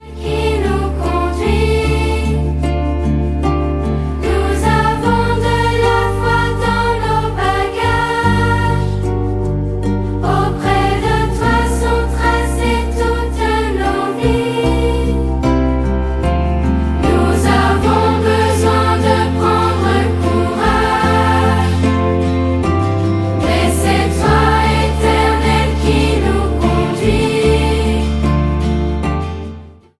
Album musical